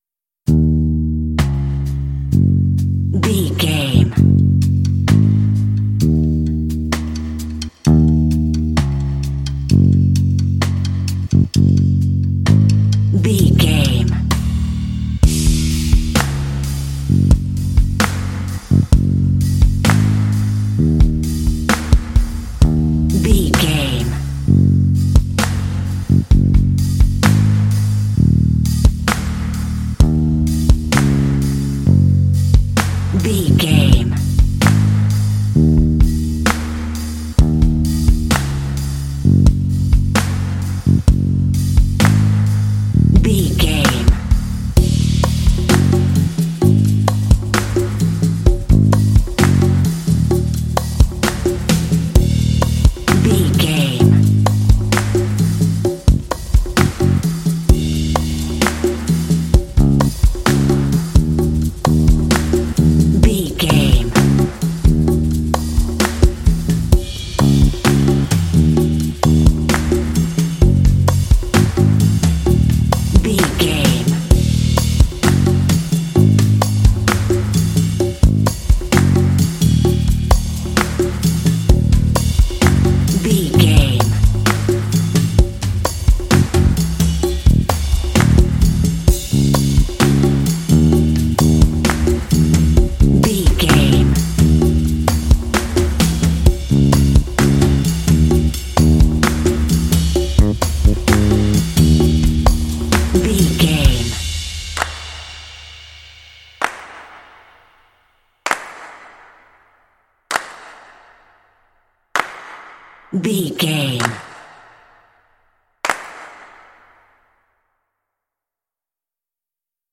Aeolian/Minor
E♭
Slow
cool
funky
bass guitar
drums
conga
hip hop